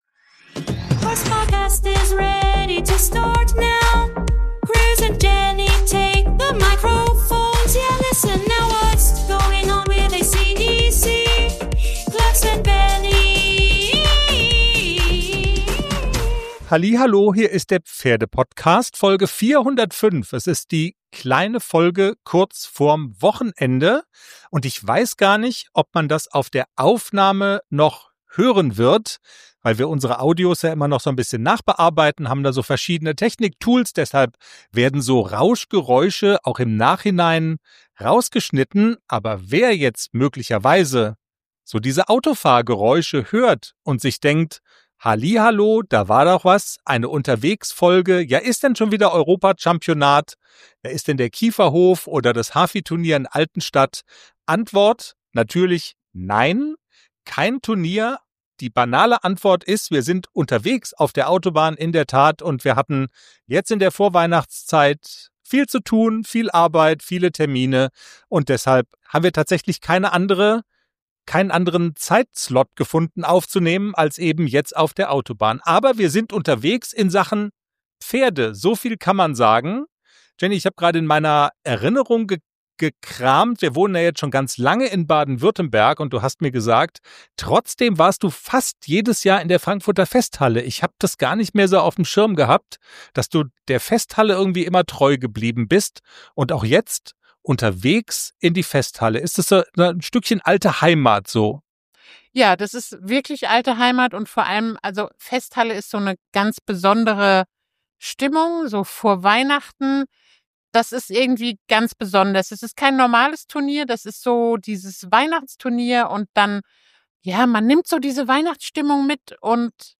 Unterwegs aufgenommen, mitten in der Vorweihnachtszeit: In Folge 405 sprechen wir über Weihnachtsstimmung im Stall, die besondere Atmosphäre der Frankfurter Festhalle und einen Heiligabend mit Pferden, Springstunde, Glühwein und Gemeinschaft.